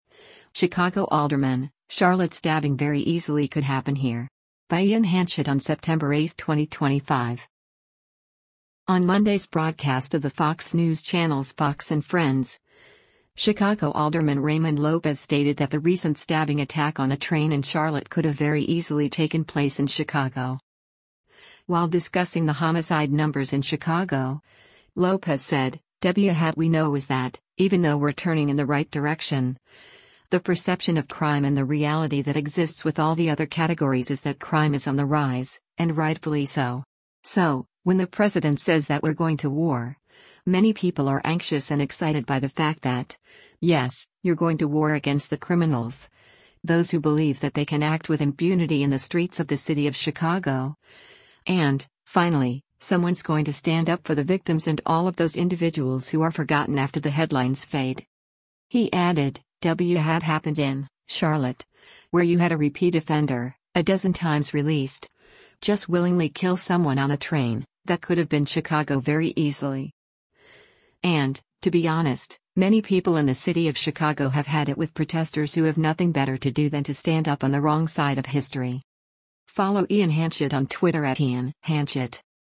On Monday’s broadcast of the Fox News Channel’s “Fox & Friends,” Chicago Alderman Raymond Lopez stated that the recent stabbing attack on a train in Charlotte could have “very easily” taken place in Chicago.